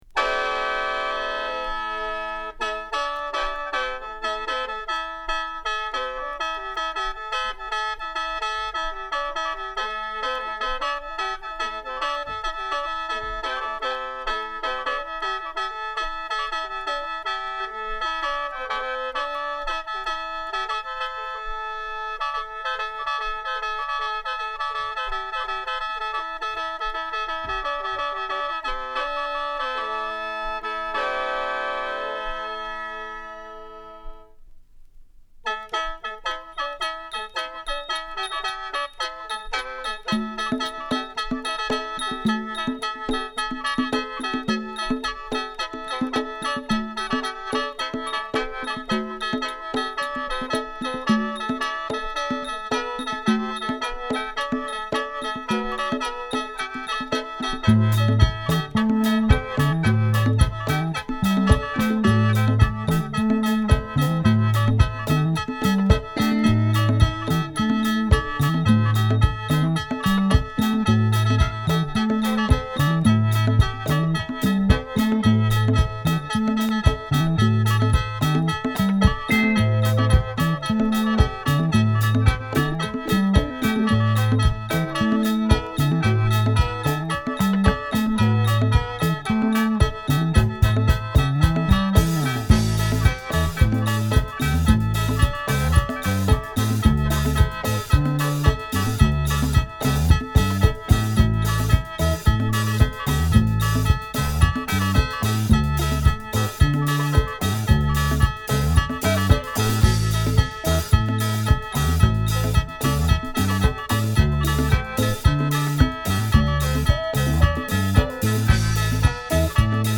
Thailand